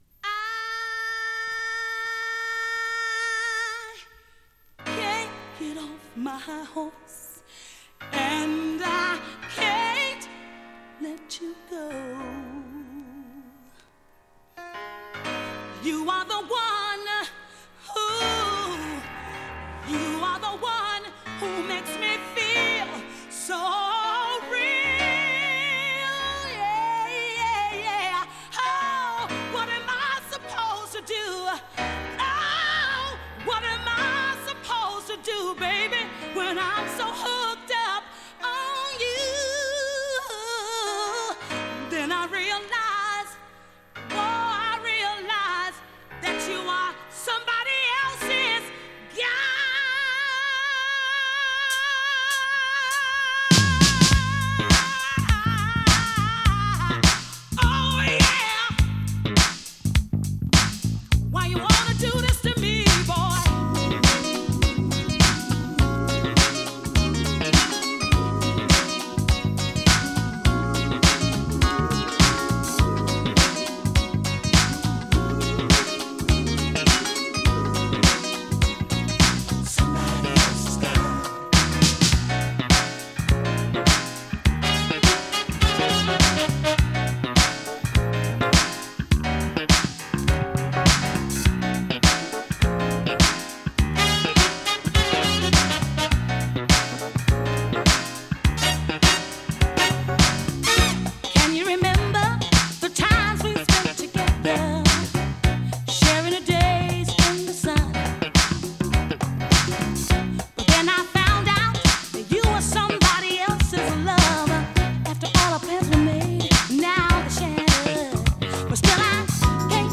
ガラージュ ディスコ
パワフルなボーカルが素晴らしいミディアム・ダンクラ定番中の定番！
パワフルなトラックを引っ張るような彼女のエモーショナルなボーカル、男女のソウルフルなコーラスが冴える名曲中の名曲！
♪Vocal (6.28)♪